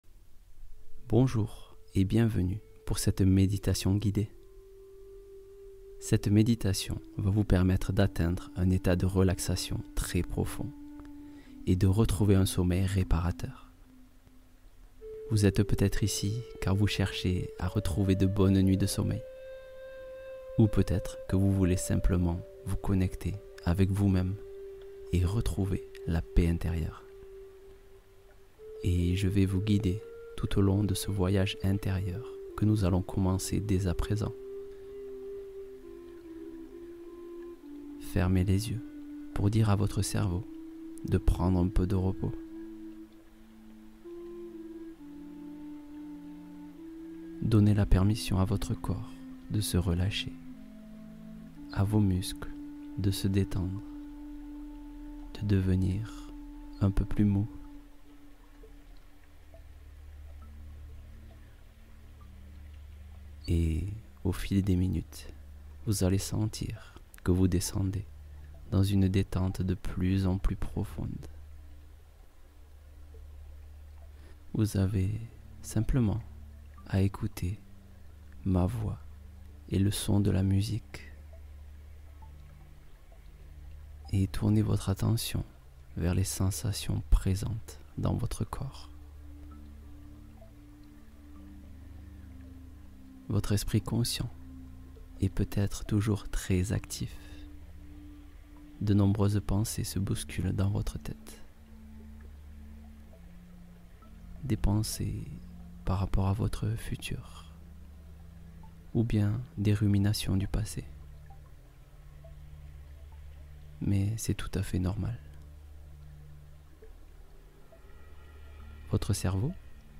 Sommeil réparateur : méditation du soir apaisante